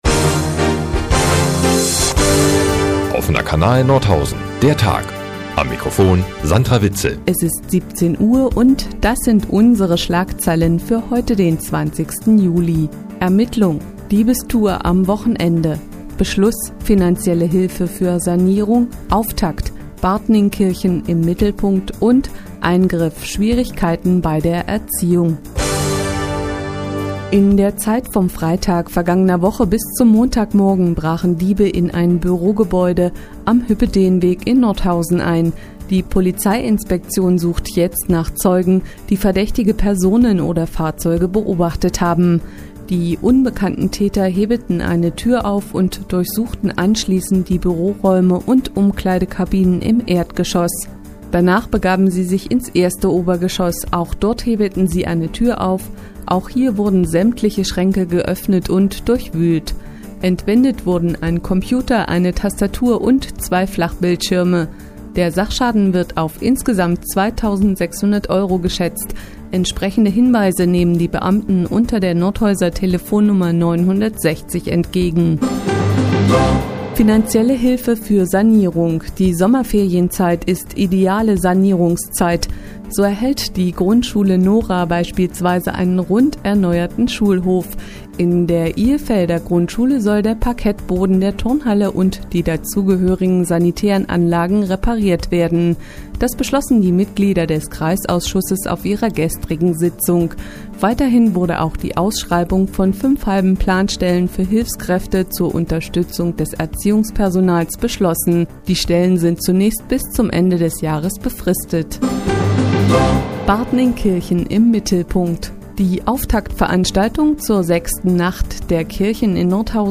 Die tägliche Nachrichtensendung des OKN ist auch in der nnz zu hören.